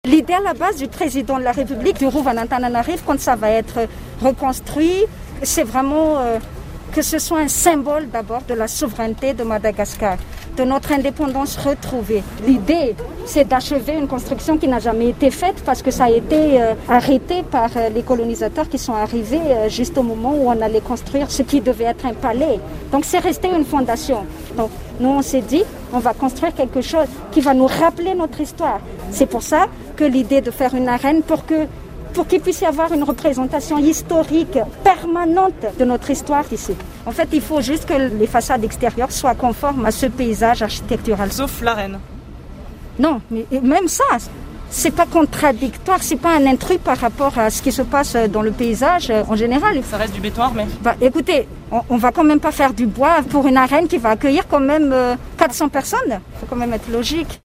La ministre de la Culture répond sur l’utilisation de béton armé au sein du Rova d’Antananarivo
Un brin énervée la ministre ?